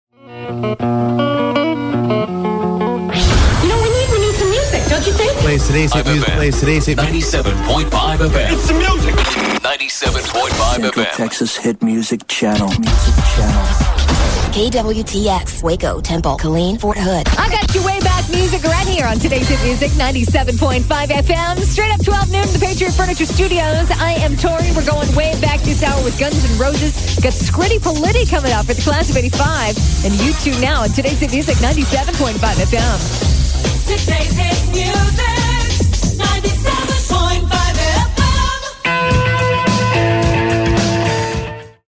KWTX-FM Top of the Hour Audio: